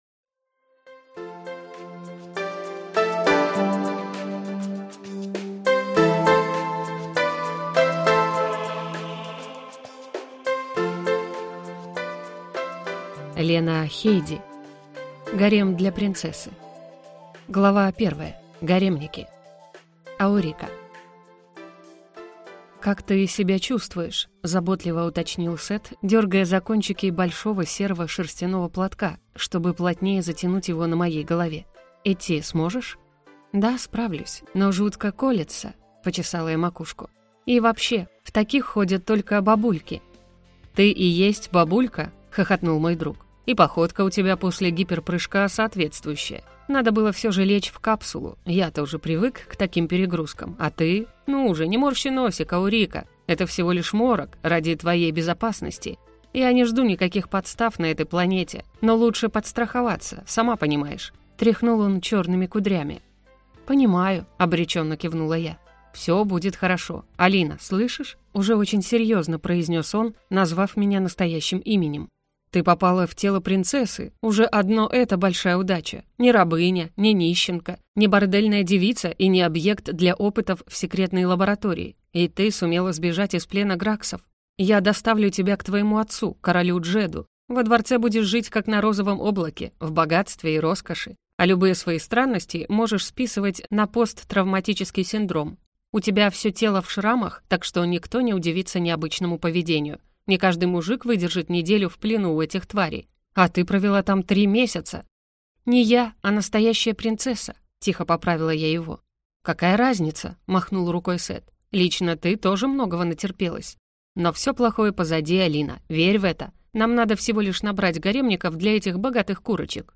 Аудиокнига Гарем для принцессы | Библиотека аудиокниг